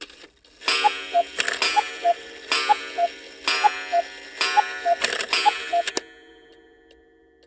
cuckoo-clock-06.wav